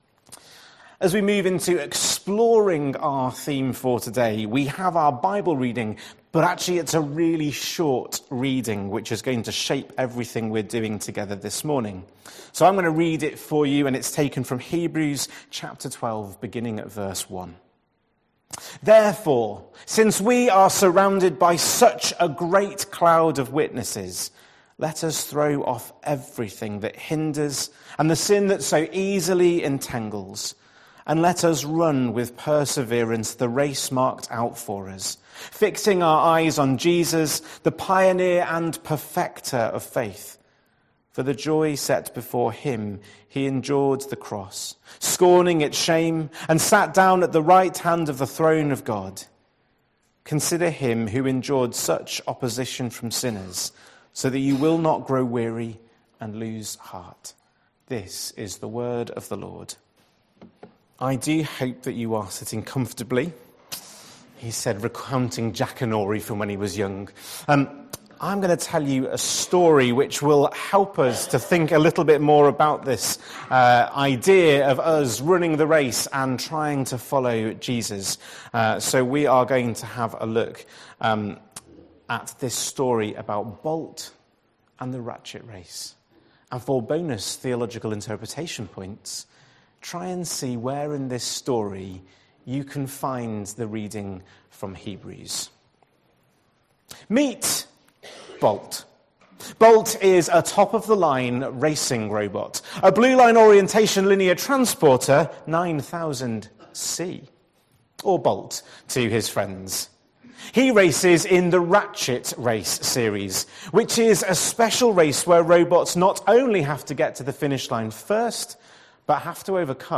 2nd November 2025 Sunday Reading and Talk. - St Luke's